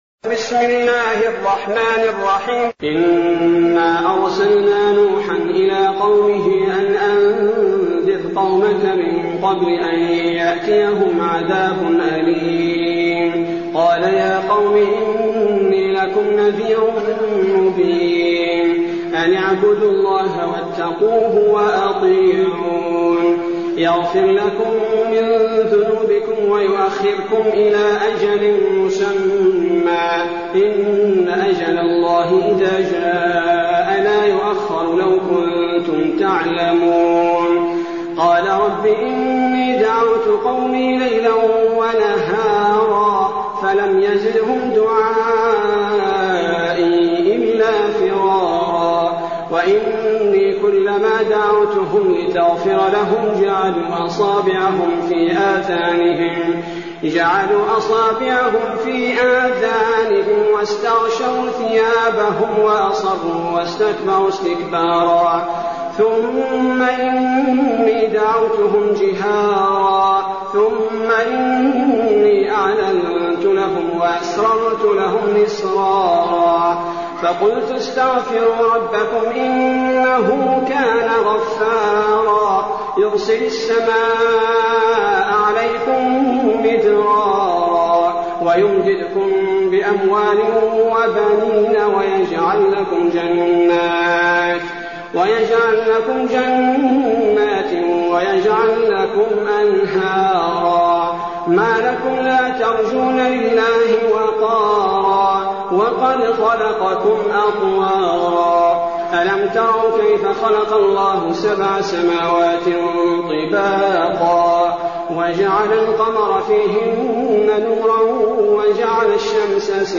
المكان: المسجد النبوي الشيخ: فضيلة الشيخ عبدالباري الثبيتي فضيلة الشيخ عبدالباري الثبيتي نوح